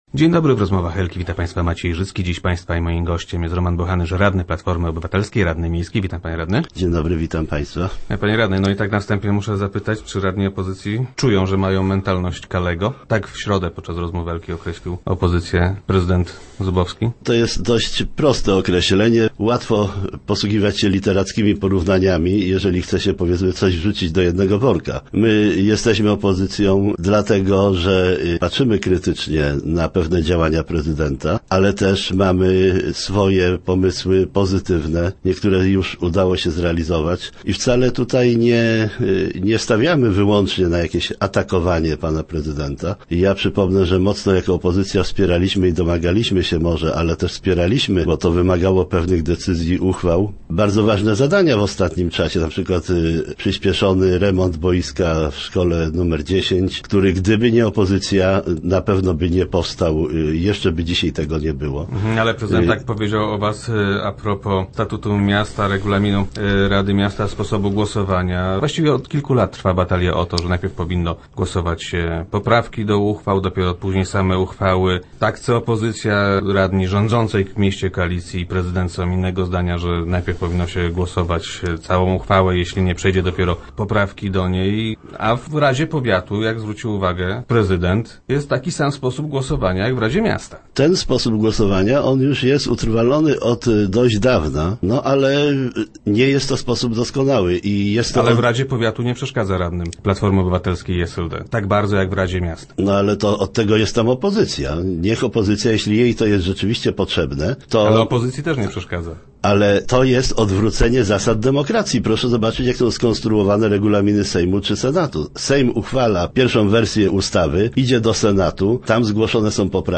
0401_bochanysz.jpgRadni opozycji nie zgadzają się ze stwierdzeniem prezydenta, że mają mentalność Kalego. - Nasza działalność nie ogranicza się jedynie do krytyki prezydenta – twierdzi Roman Bochanysz, radny PO, który był gościem piątkowych Rozmów Elki.
Boi się bowiem, że jego zwolennicy uznaliby niektóre z naszych poprawek za zasadne - stwierdził radny PO w radiowym studio.